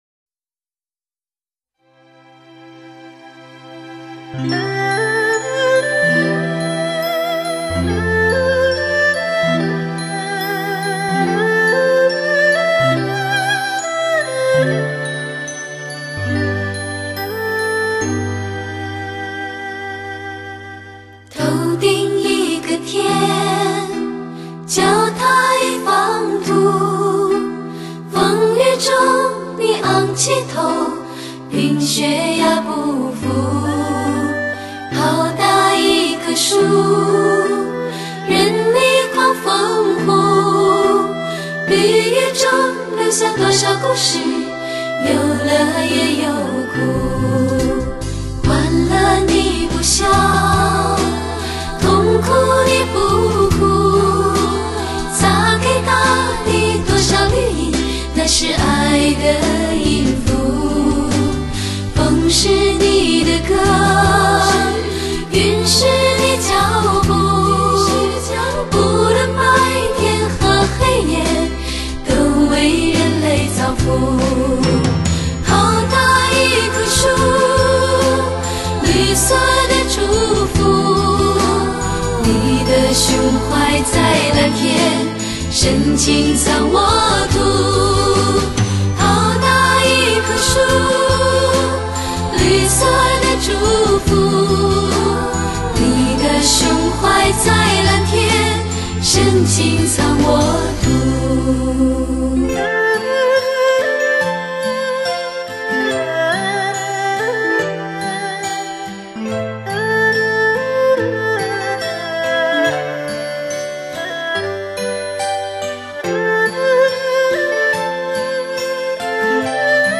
演唱歌手：组合演唱
在亚音西化、东西合璧的典范旋律里感受和声的芬芳，
音色坚实，华丽而又流畅，并富有准确的声音表情。